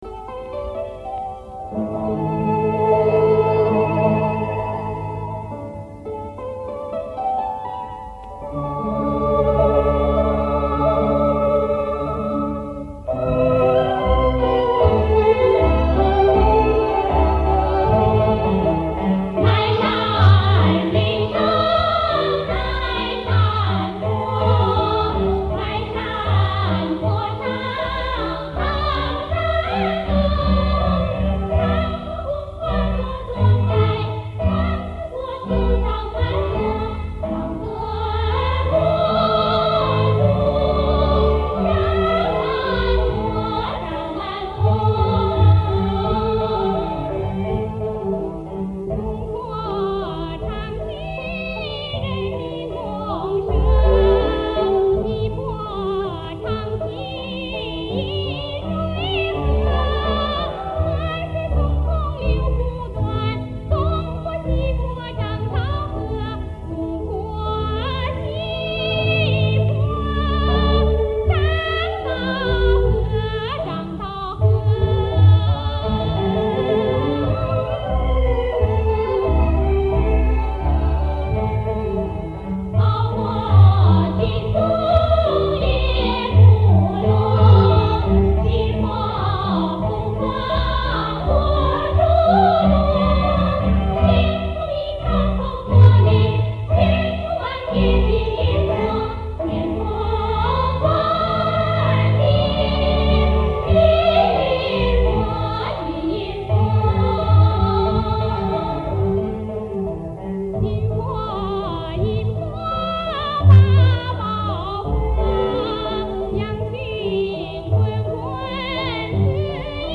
山西昔阳县民歌
1964年录音